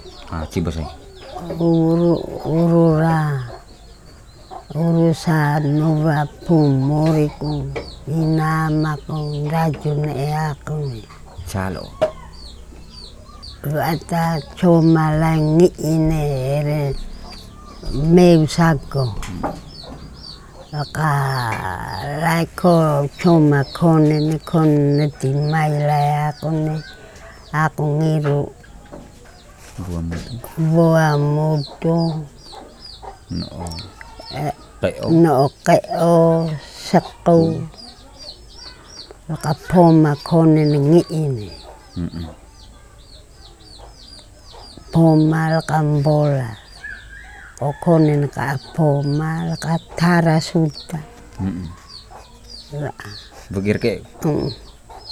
Palu'e, Flores, Nusa Tenggara Timur, Indonesia. Recording made in kampong Kaju keri, Keli domain.